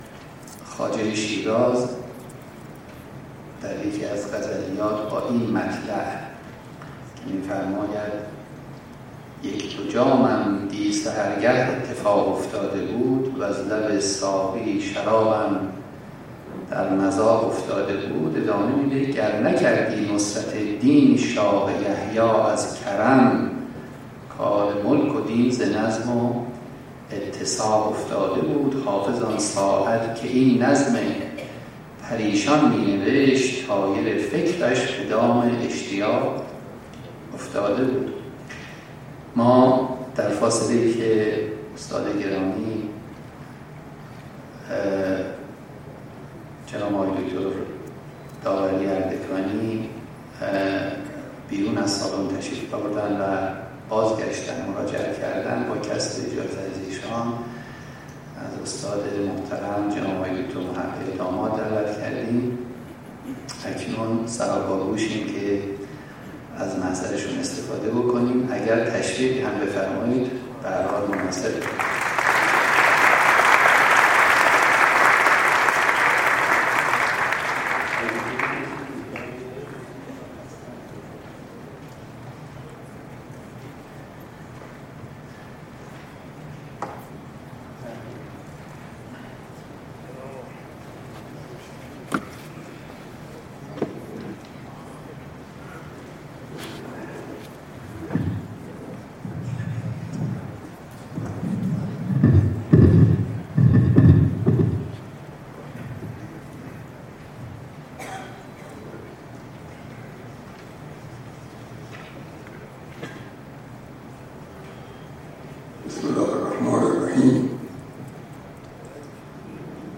فرهنگ امروز: فایل حاضر سخنرانی «رضا داوری اردکانی» در نوزدهمین همایش ملاصدرا با عنوان «نظم در فلسفه یونانی» است. این همایش به همت بنیاد حکمت اسلامی صدرا ۳۱ اردیبهشت ماه ۹۴ در این مرکز برگزار شد.